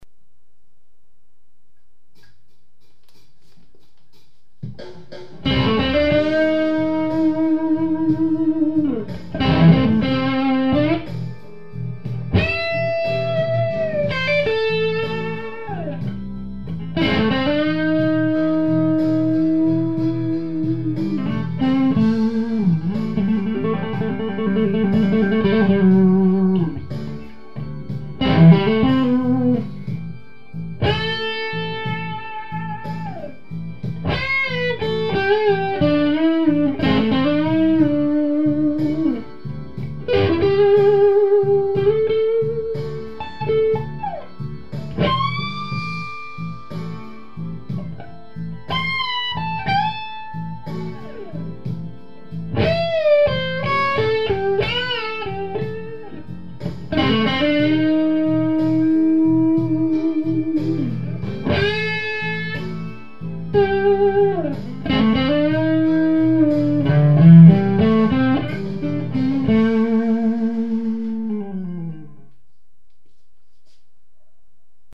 tu je moja odozva na ten bluesovy podklad
je to vpodstate len cisty kanal, hooodne nahlas - takze nakonci chytil spatnu vazbu asi radiator  ?§!.
Co sa tyka nahravania, sral som na to, pustil som podklad na reprakoch na plne a steloval som mikrofon v priestore aby zachytila dobre gitaru.
bluesin.mp3